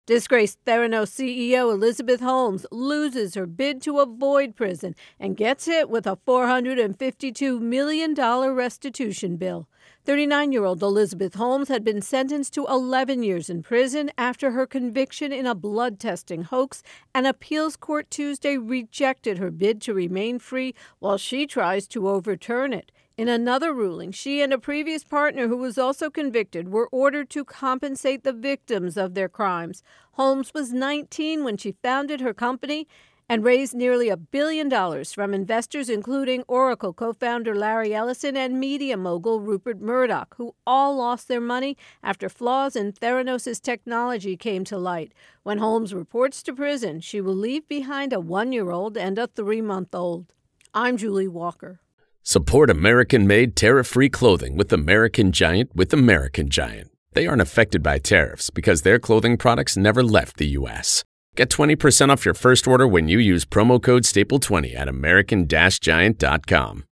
reports on Theranos Fraud Holmes Prison